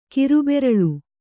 ♪ kiru beraḷu